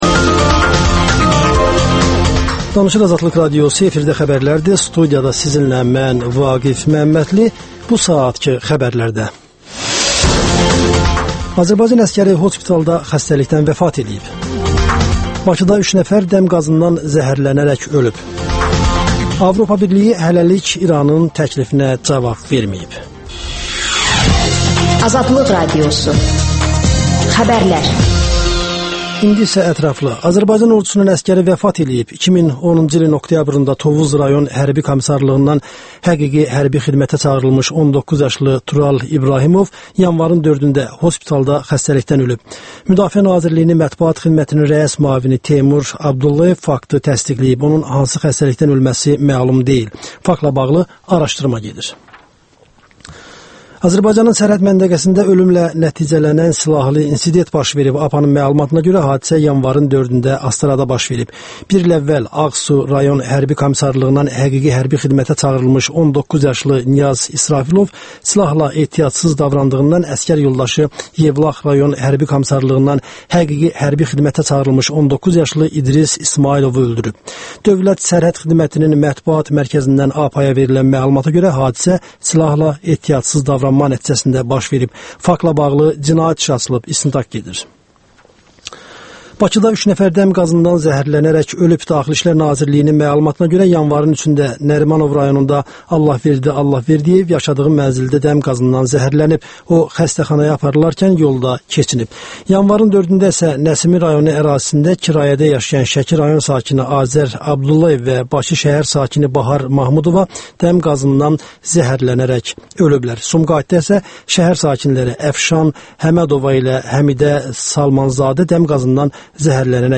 Professor Cəmil Həsənli bu mövzuda suallara cavab verir